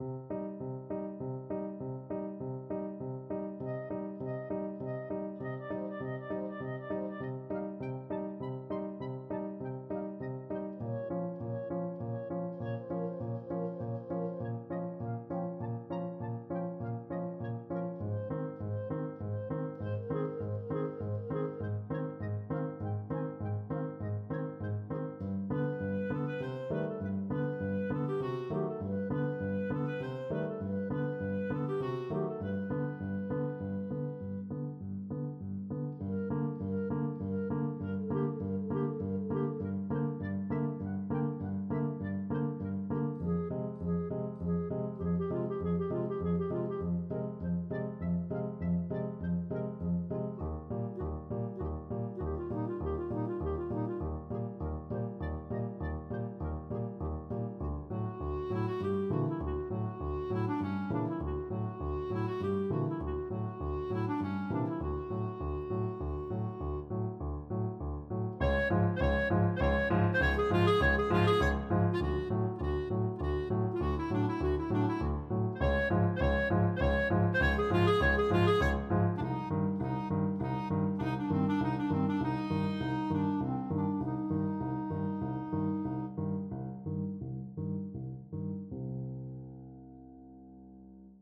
Free Sheet music for Flute-Clarinet Duet
C minor (Sounding Pitch) D minor (Clarinet in Bb) (View more C minor Music for Flute-Clarinet Duet )
3/4 (View more 3/4 Music)
Andantino ( = 100) (View more music marked Andantino)
Classical (View more Classical Flute-Clarinet Duet Music)